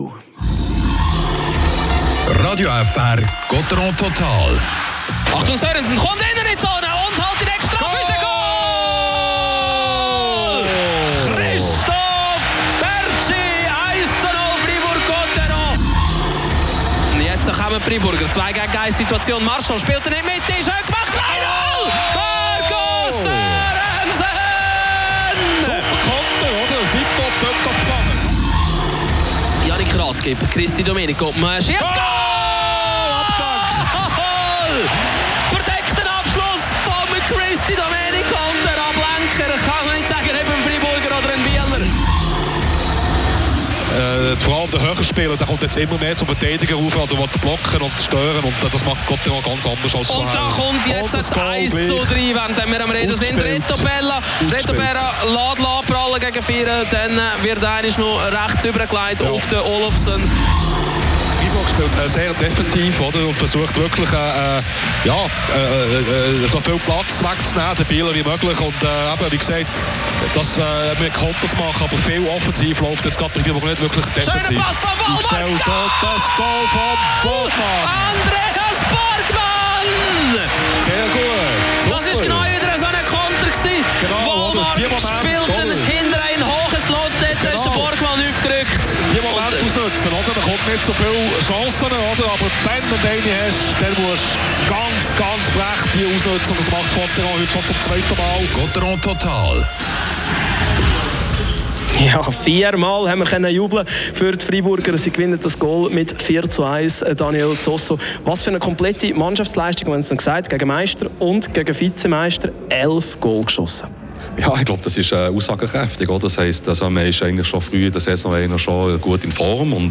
Interview mit dem